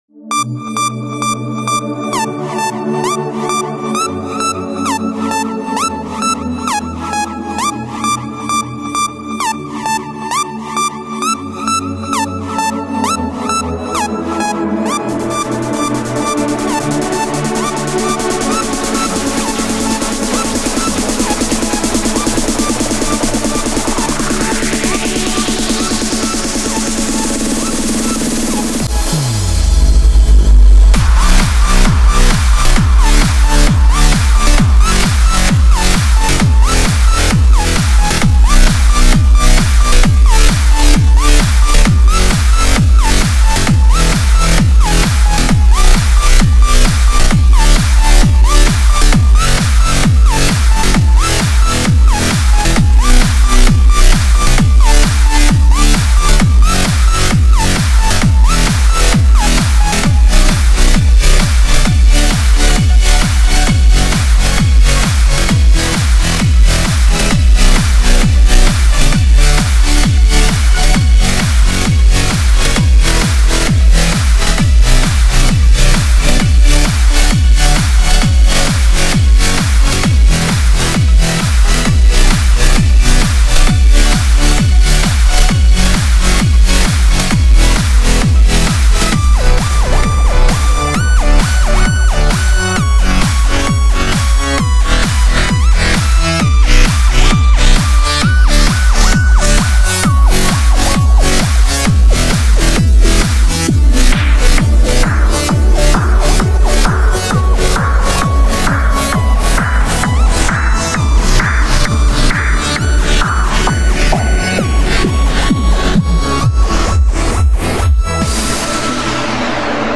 Категория: Electro